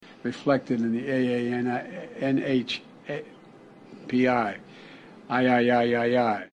• Качество: 320, Stereo
мужской голос
забавные
голосовые